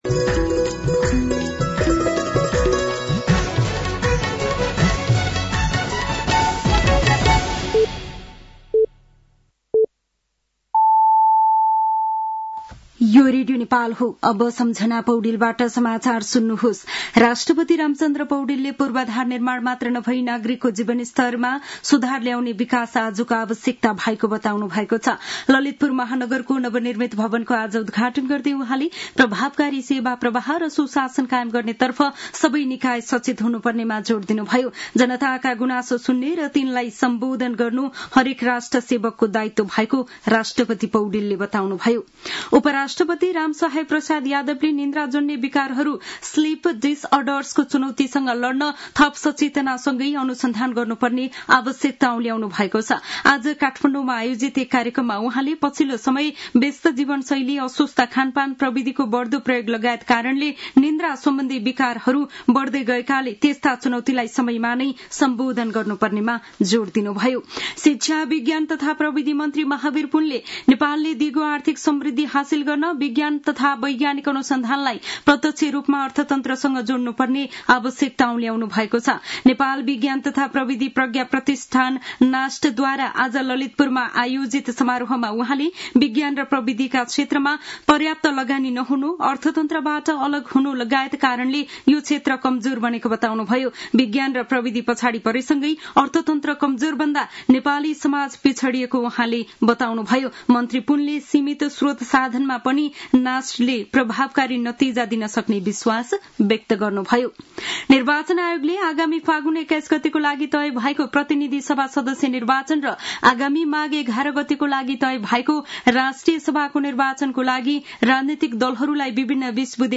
साँझ ५ बजेको नेपाली समाचार : २० मंसिर , २०८२